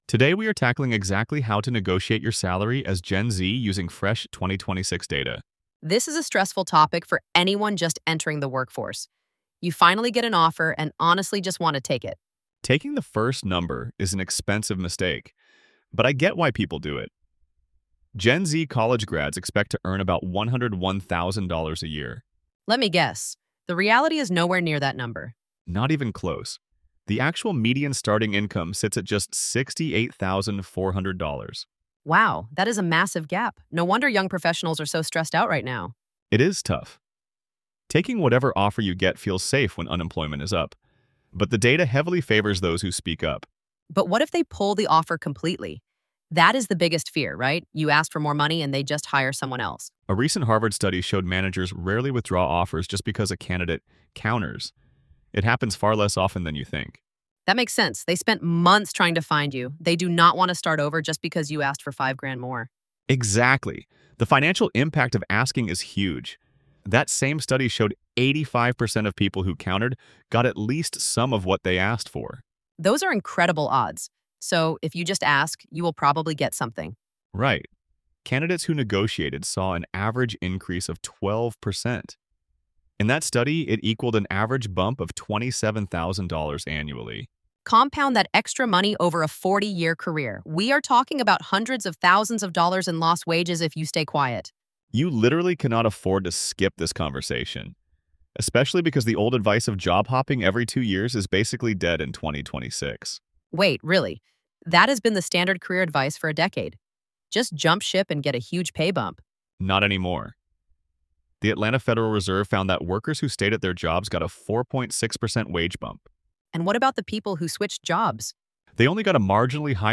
AI-generated audio · Voices by ElevenLabs